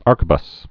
(ärkə-bəs, -kwə-)